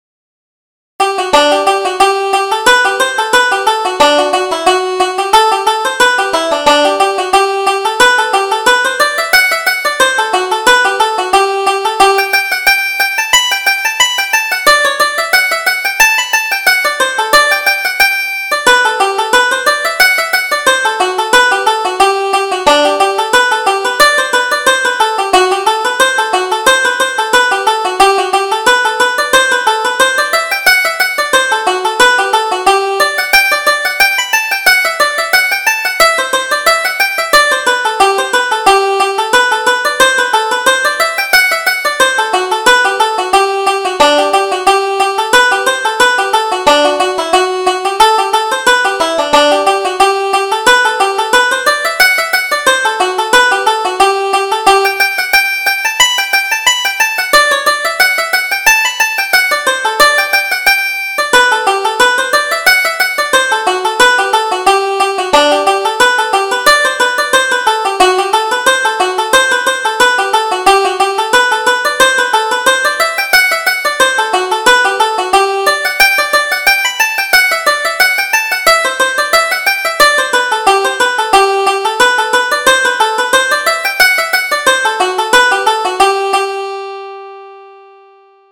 Reel: Cronin's Favorite